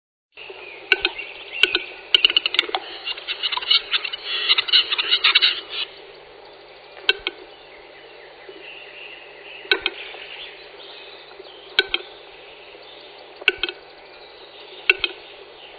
Auerhahn
Der große Auerhahn macht schnalzende, klappernde Töne, wenn er seinen Schwanz zum Rad schlägt und nach einem Weibchen balzt. Europas größter Hühnervogel wird rund 3,5 bis 6 Kilo schwer, also etwa so groß wie eine Gans.
auerhahn.mp3